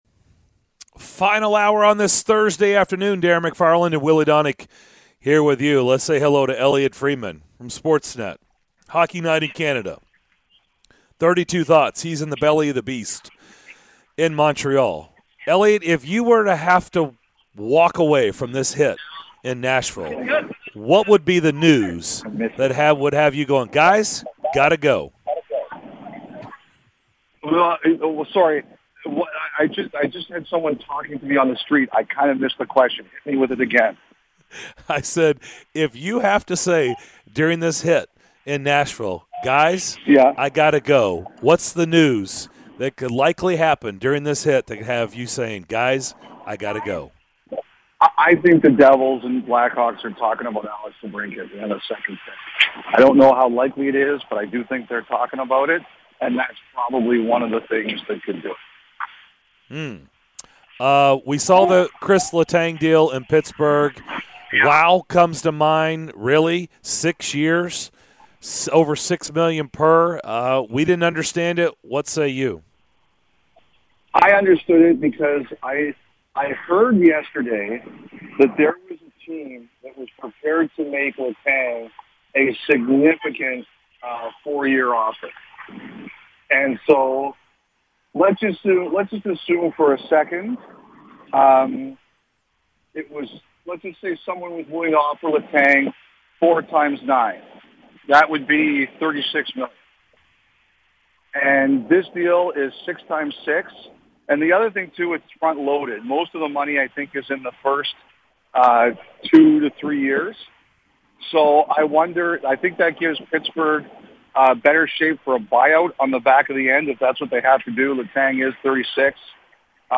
The guys talk to NHL insider, Elliotte Friedman, about NHL offseason moves and discuss which lineups the Predators will use next season